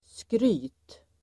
Uttal: [skry:t]